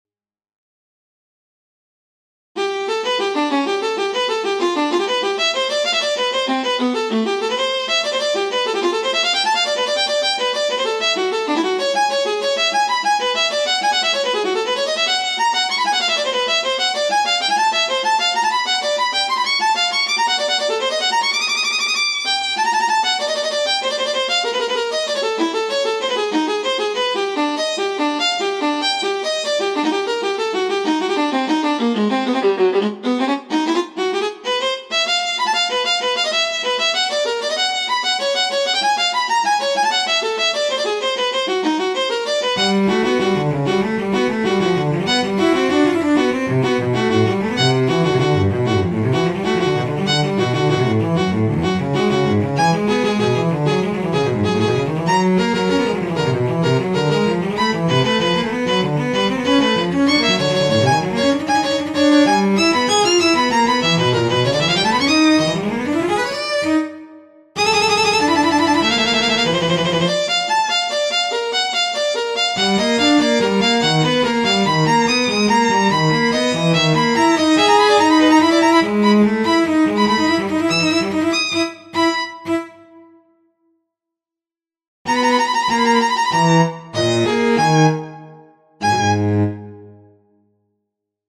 This is made with midi orchestrator +. I added some dynamics. The first part has just dynamics mf but Players can freely use force to emphasize some passages.